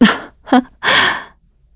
Isolet-Vox-Laugh2_kr.wav